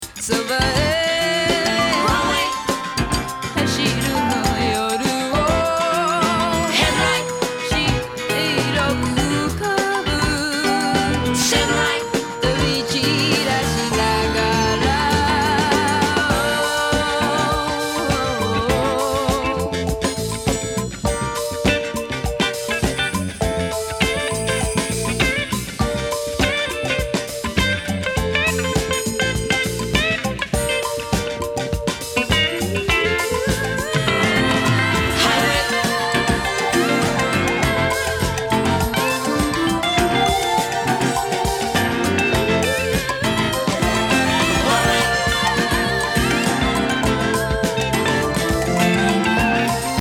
国産メロウ・ソウル傑作！
メロー・グルーヴにボーカル、各楽器の音色が極上。”